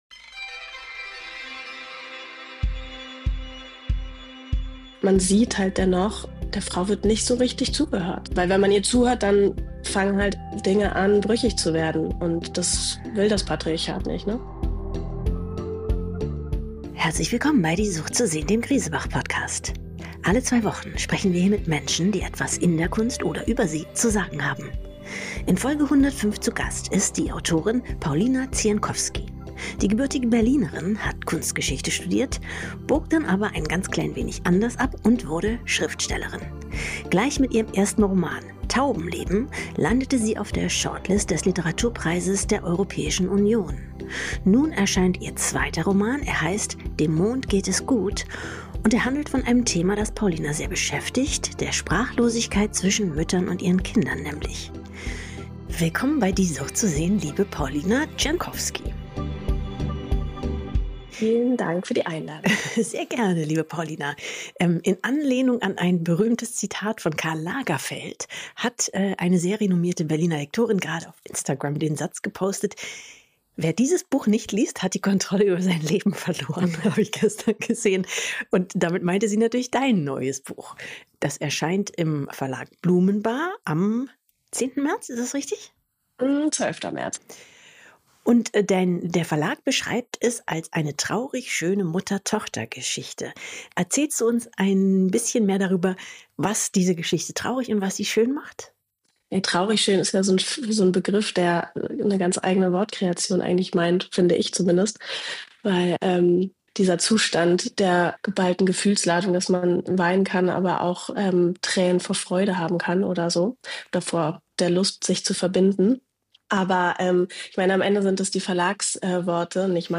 Alle zwei Wochen sprechen wir hier mit Menschen, die etwas in der Kunst - oder über sie - zu sagen haben.